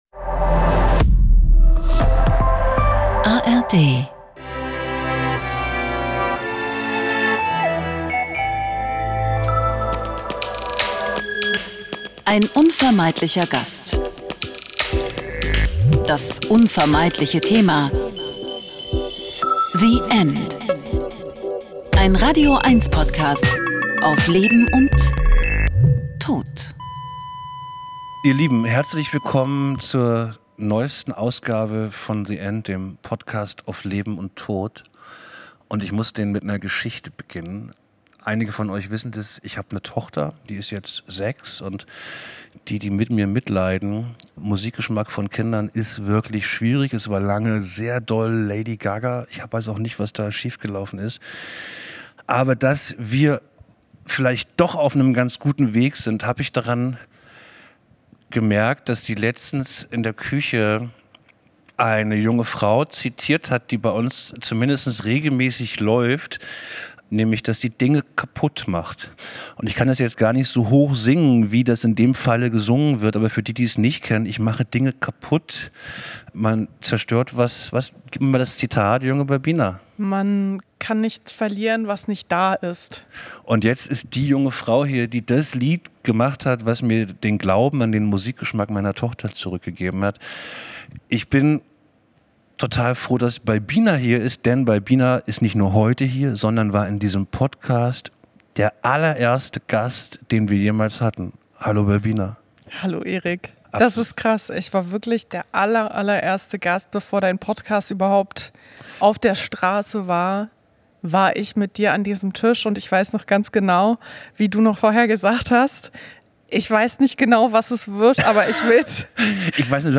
Ein Gespräch über Vaterlosigkeit, Scham, verpasste Chancen, aber auch über Leichtigkeit, Befreiung und eine ganz besondere Art der „Bestattung“.